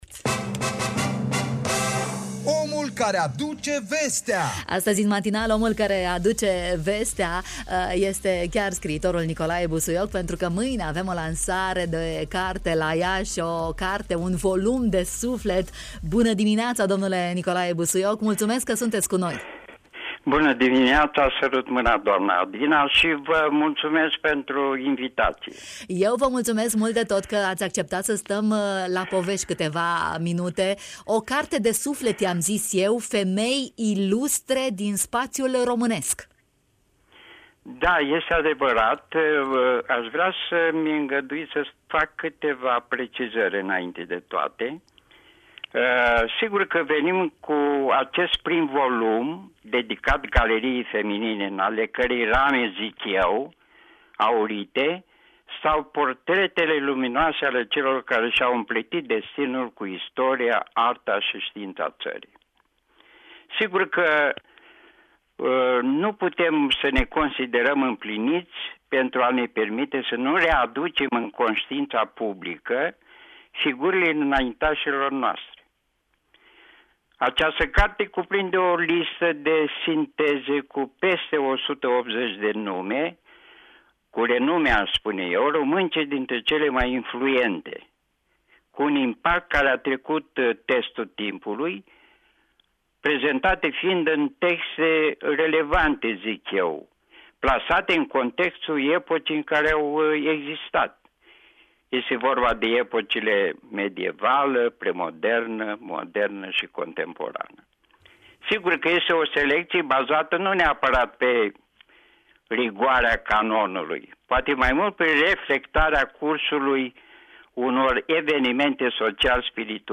la microfonul Radio România Iași